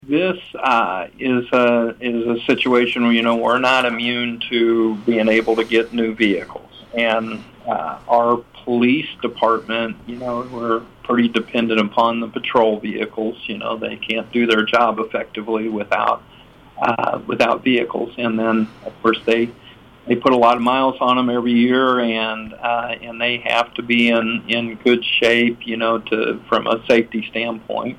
City Administrator Kelvin Shaw says its important for the police department to have reliable vehicles.